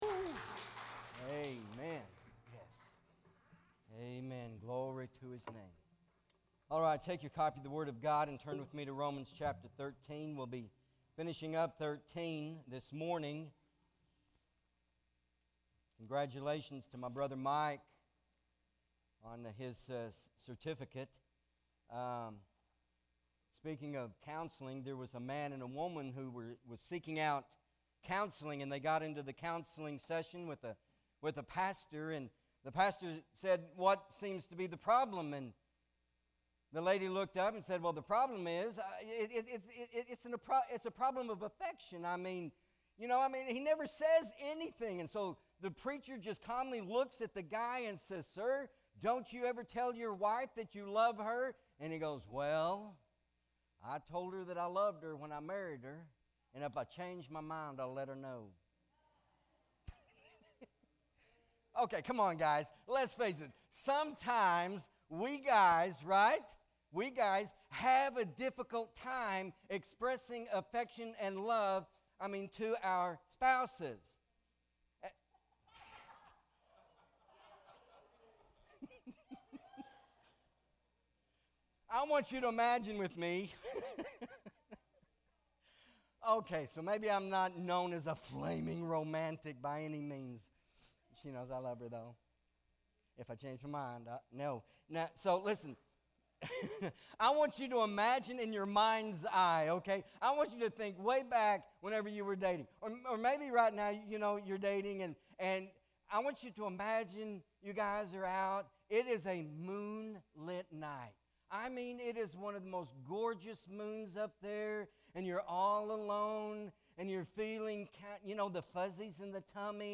Romans Revealed- The Debt of Love MP3 SUBSCRIBE on iTunes(Podcast) Notes Sermons in this Series Romans 13:8-14 Not Ashamed!